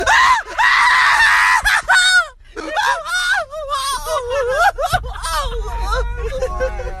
Risada Zóio
Risada do youtuber Everson Zoio
risada-do-zoio.mp3